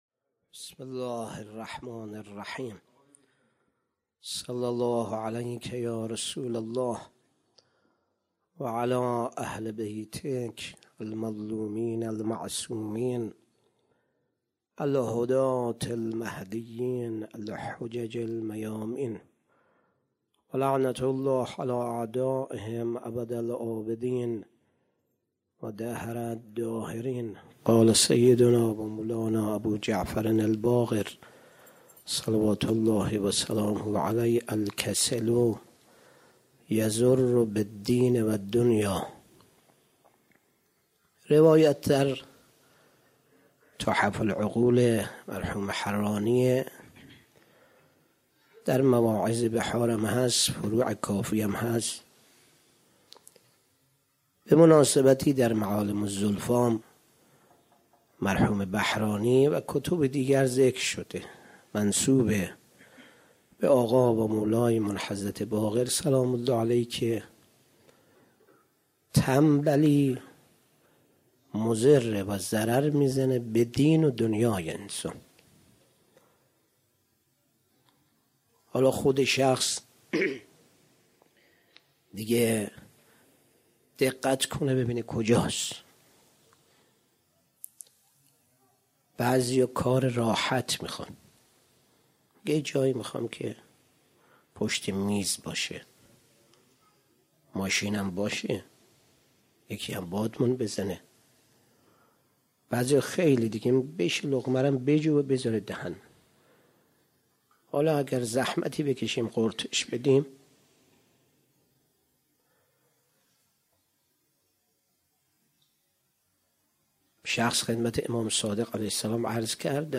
27 مرداد 97 - غمخانه بی بی شهربانو - سخنرانی